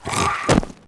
Heroes3_-_Infernal_Troglodyte_-_DeathSound.ogg